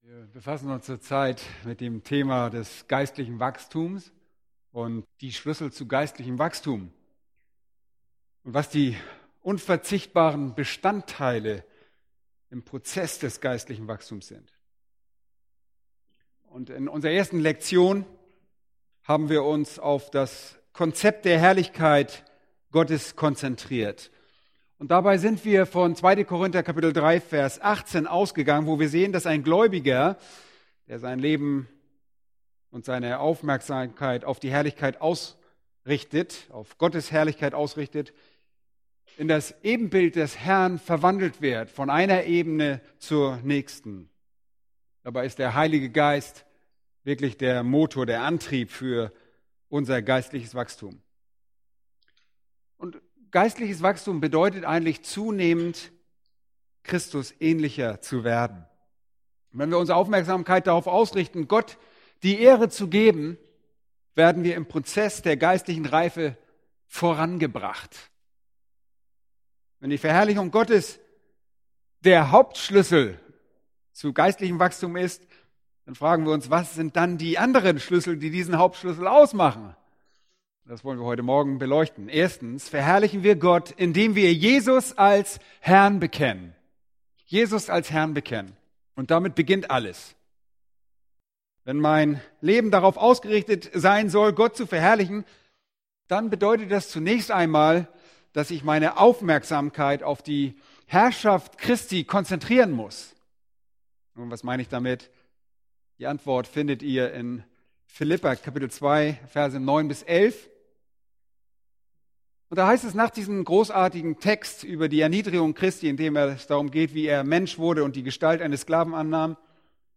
Predigten Übersicht nach Serien Startseite Predigt-Archiv Predigten Übersicht nach Serien